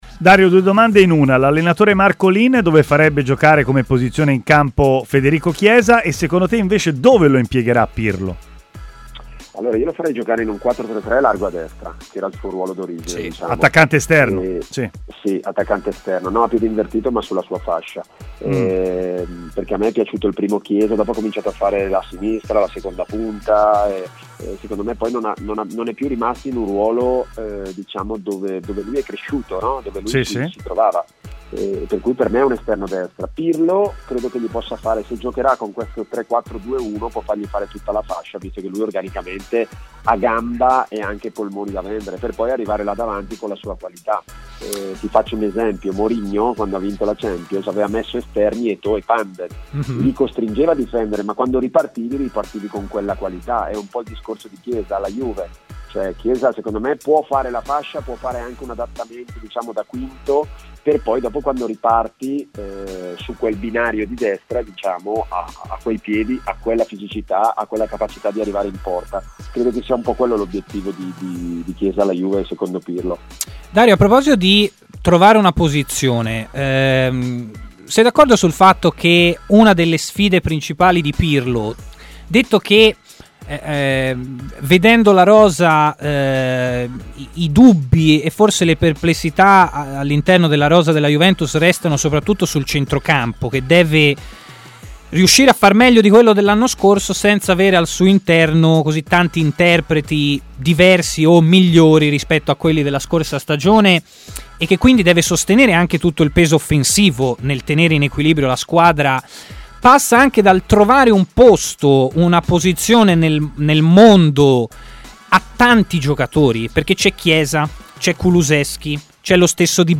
L'ex allenatore in seconda della Fiorentina con Mihajlovic, Dario Marcolin, ha parlato a TMW Radio, nella trasmissione "Stadio Aperto", del possibile utilizzo di Chiesa da parte di Pirlo: "Io lo metterei nel suo ruolo d'origine, attaccante esterno a destra, non da laterale invertito.